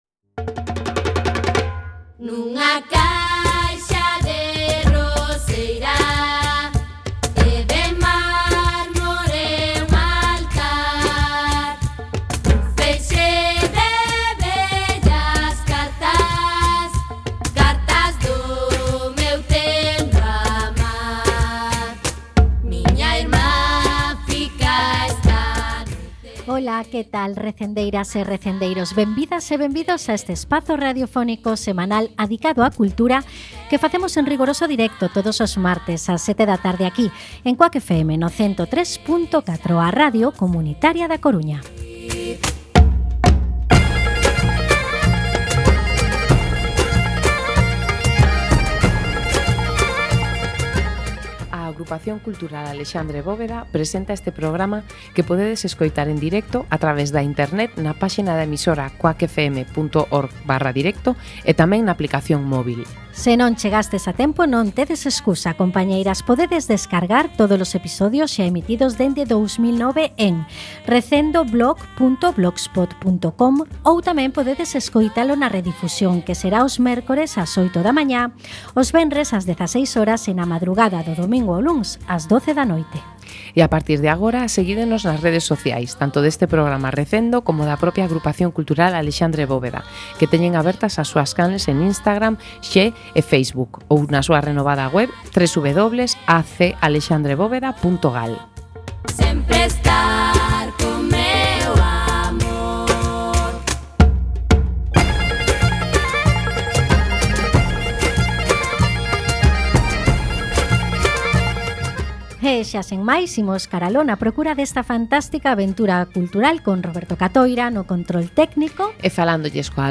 16x30 Entrevista